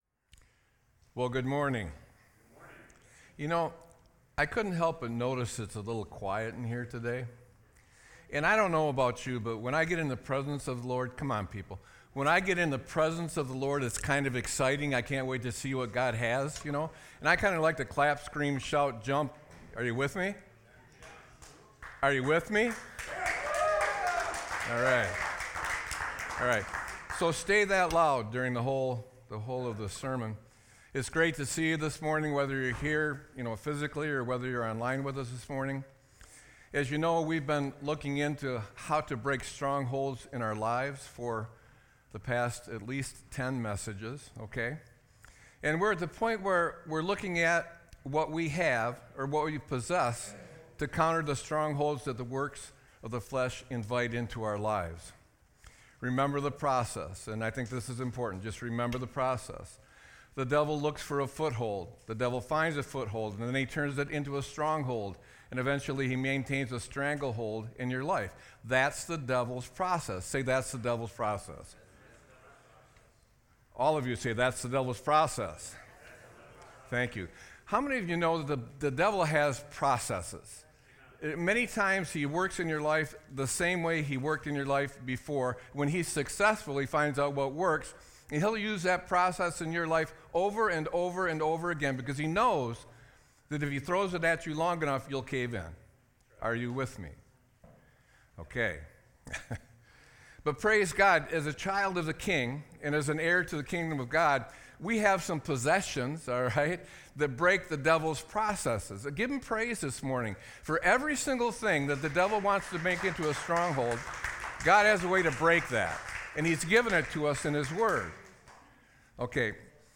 Sermon-9-22-24.mp3